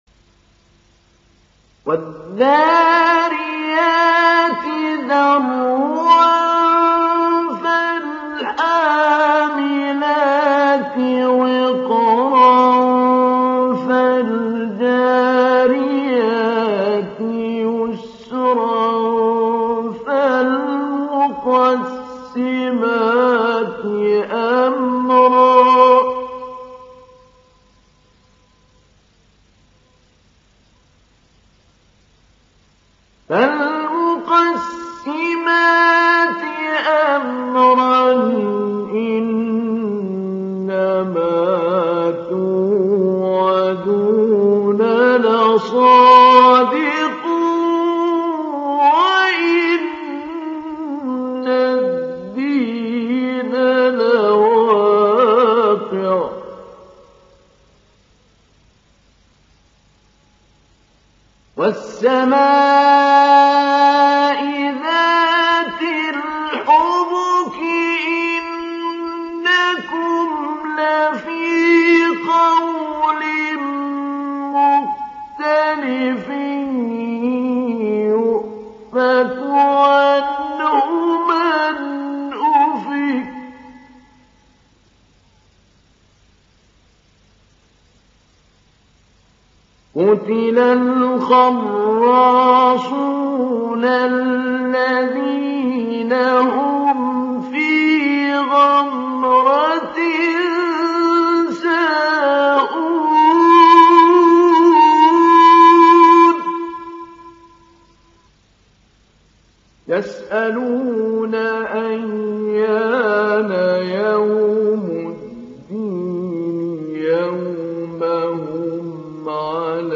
تحميل سورة الذاريات mp3 بصوت محمود علي البنا مجود برواية حفص عن عاصم, تحميل استماع القرآن الكريم على الجوال mp3 كاملا بروابط مباشرة وسريعة
تحميل سورة الذاريات محمود علي البنا مجود